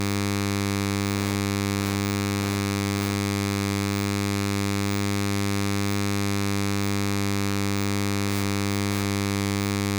I managed to get some sound recorded using the codec now. I have attached two 96 kHz wav files, one the original recording from the codec, the other one a clipped version.
noisynoise.wav